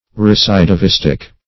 -- Re*cid`i*vis"tic
(r[-e]*s[i^]d`[i^]*v[i^]s"t[i^]k), a.